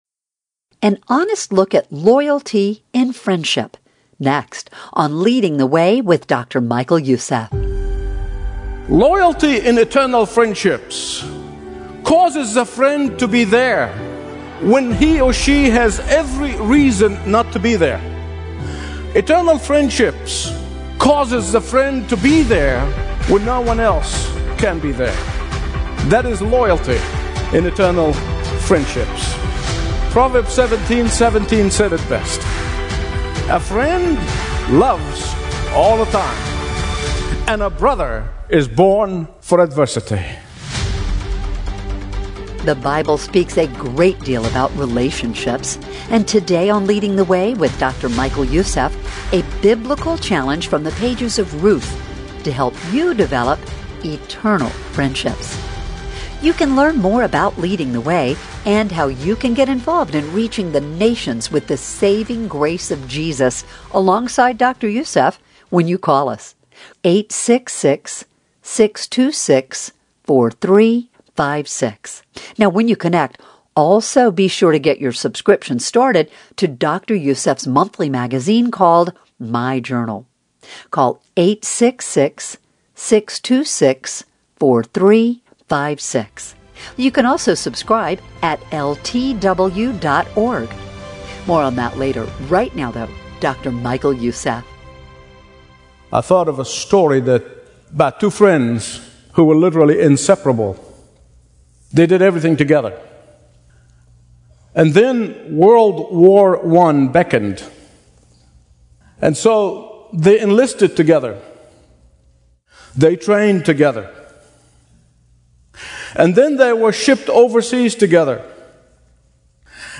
Daily Bible Teachings
Stream Expository Bible Teaching & Understand the Bible Like Never Before